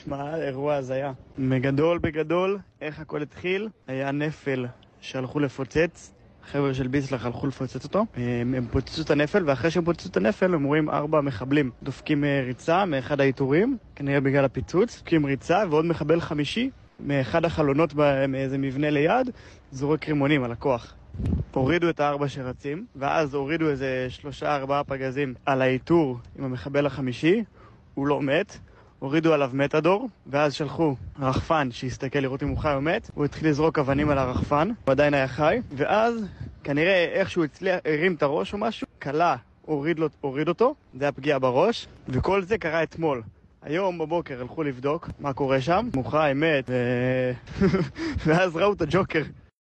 אחד מהלוחמים שהיה בחיסול של סינוואר מספר איך הכול קרה